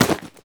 foley_object_grab_pickup_rough_05.wav